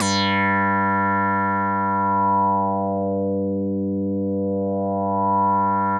G3_raspy_synth.wav